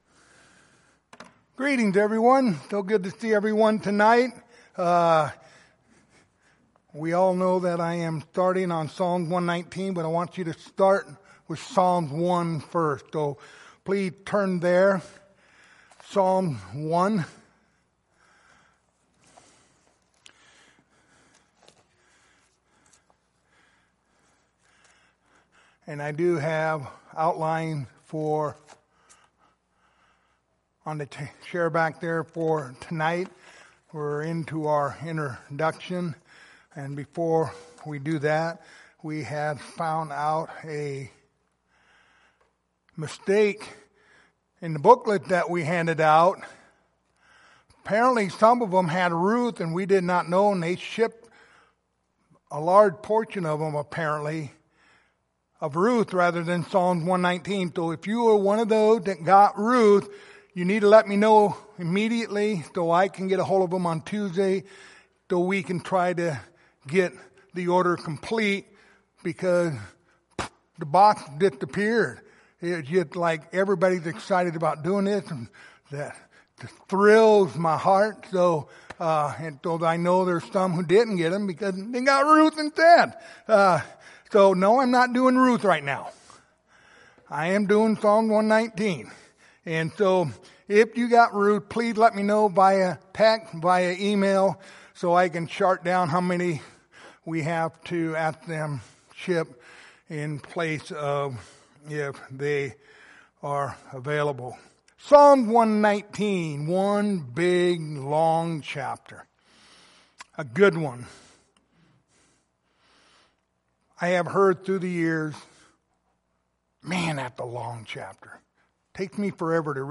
Psalm 119:1-2 Service Type: Sunday Evening Topics